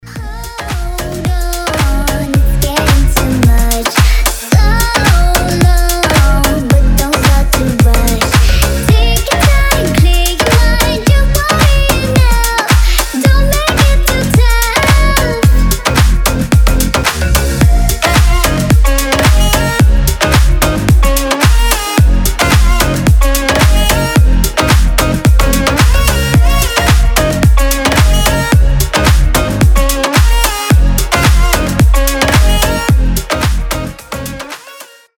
• Качество: 320, Stereo
женский голос
Club House
Классная танцевально-клубная музыка для звонка телефона